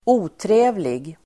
Uttal: [²'o:tre:vlig]